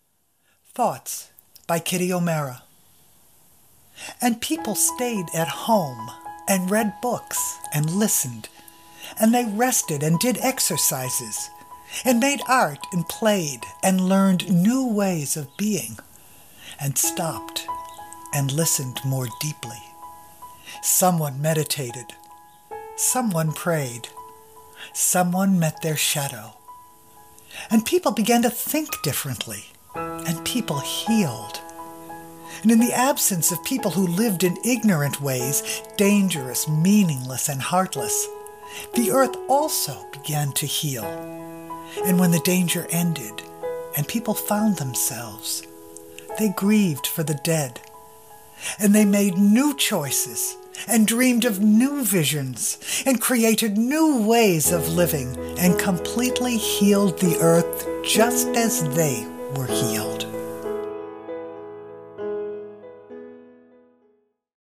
Poetry
2-minutes-Thoughts-MusicMusic-for-Stress-ReliefInstrumental-Music.mp3